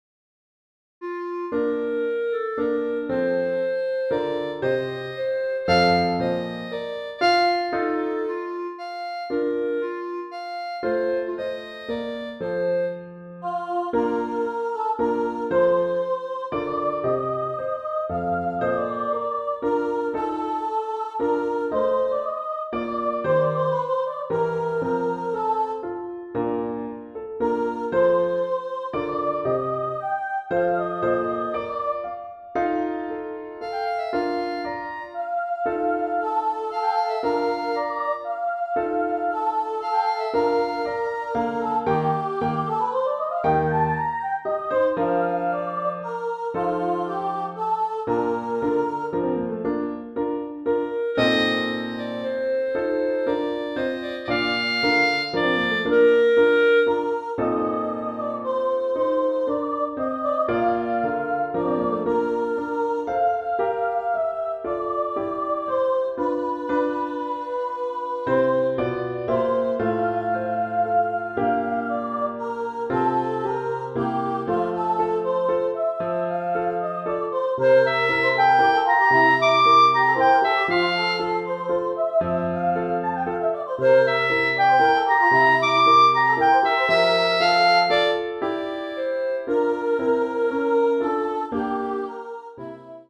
Voicing: Mixed Ensemble